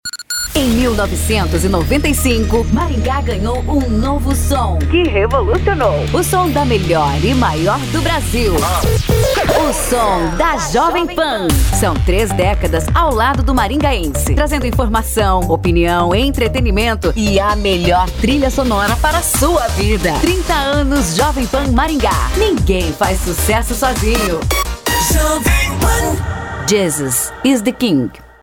Vinheta: